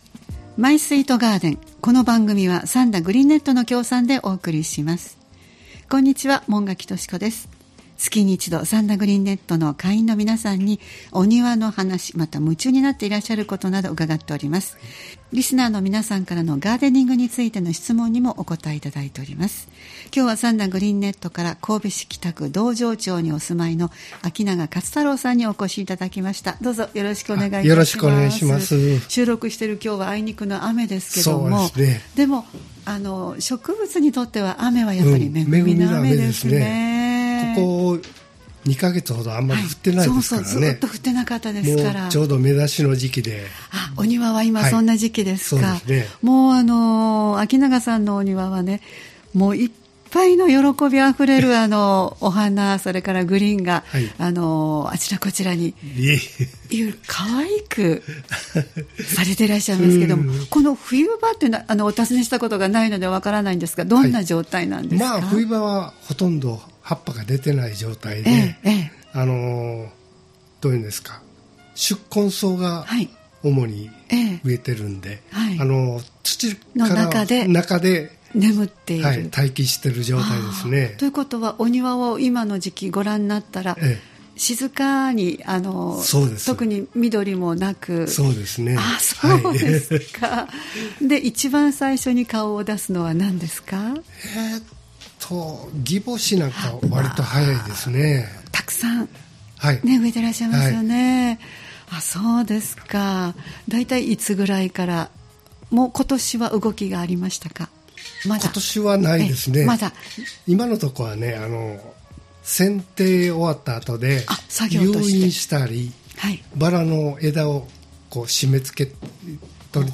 毎月第2火曜日は兵庫県三田市、神戸市北区、西宮市北部でオープンガーデンを開催されている三田グリーンネットの会員の方をスタジオにお迎えしてお庭の様子をお聞きする「マイスイートガーデン」（協賛：三田グリーンネット）をポッドキャスト配信しています（再生ボタン▶を押すと番組が始まります）